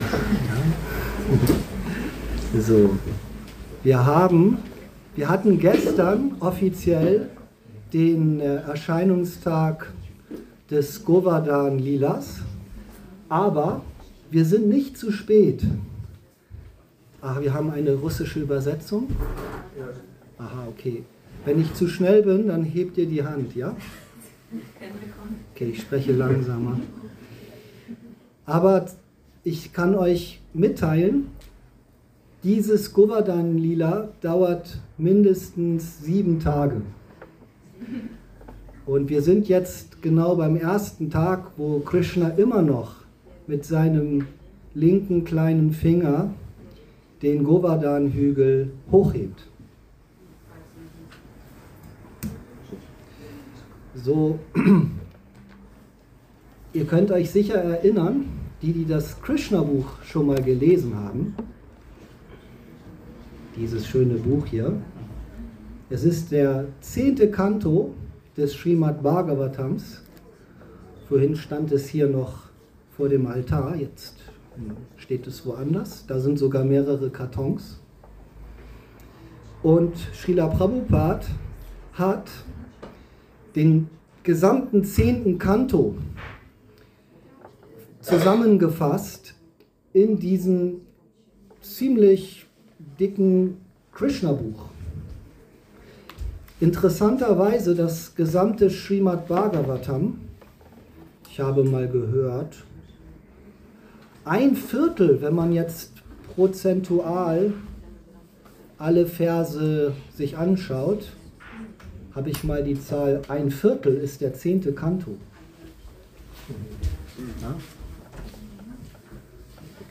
Vorträge im Bhakti Yoga Zentrum Hamburg Podcast